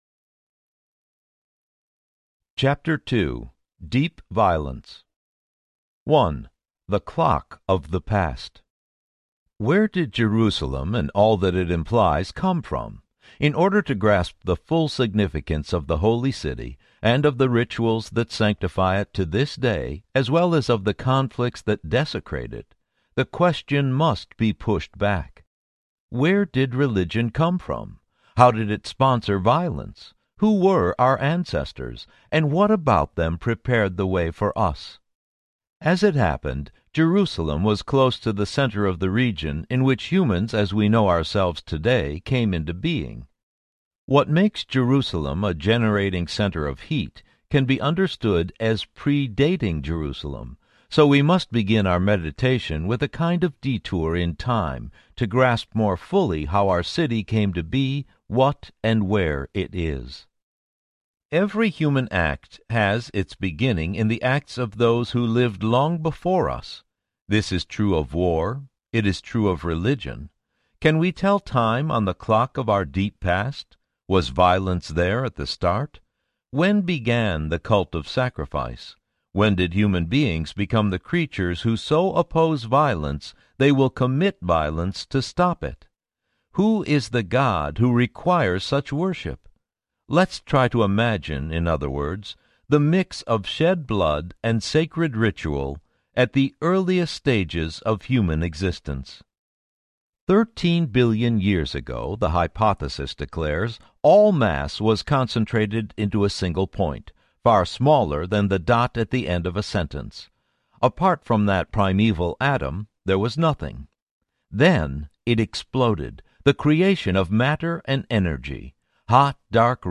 Jerusalem, Jerusalem Audiobook
Narrator
17.0 Hrs. – Unabridged